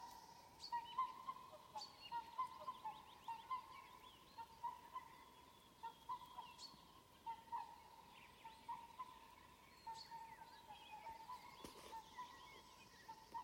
Ziemeļu gulbis, Cygnus cygnus
StatussDzirdēta balss, saucieni